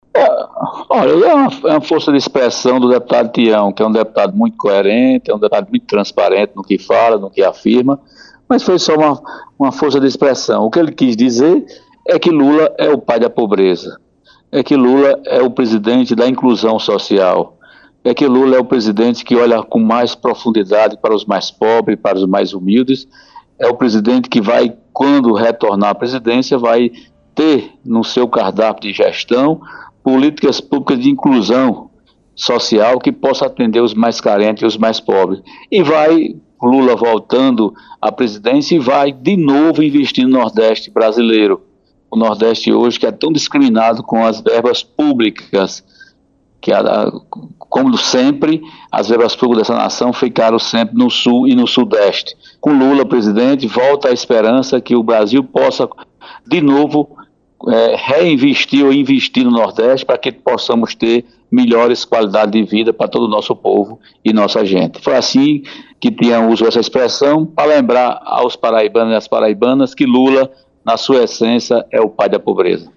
O presidente da Assembleia Legislativa da Paraíba (ALPB), deputado estadual Adriano Galdino (Republicanos), disse nesta segunda-feira (24), em entrevista à Rádio Arapuan FM, que a declaração do deputado estadual Tião Gomes (PSB), que segeriu “pisa” nos eleitores pobres que votam no presidente Jair Bolsonaro (PL), foi apenas uma “força de expressão”.